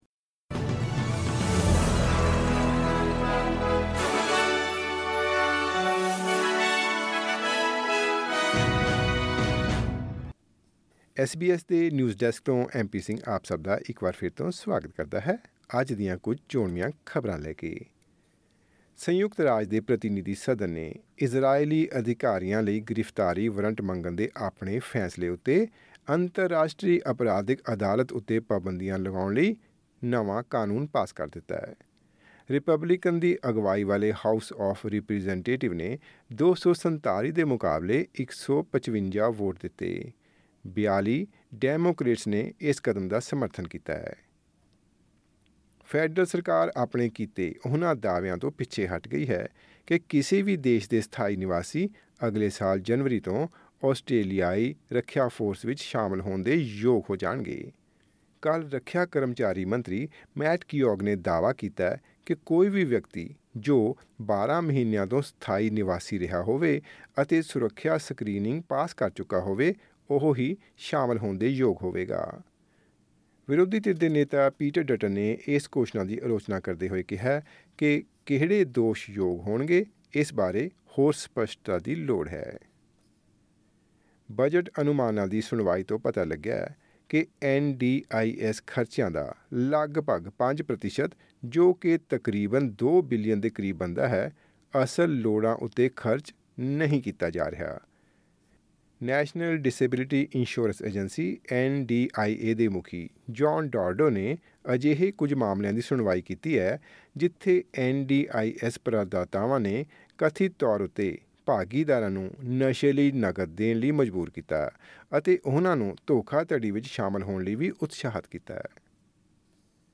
ਐਸ ਬੀ ਐਸ ਪੰਜਾਬੀ ਤੋਂ ਆਸਟ੍ਰੇਲੀਆ ਦੀਆਂ ਮੁੱਖ ਖ਼ਬਰਾਂ: 5 ਜੂਨ, 2024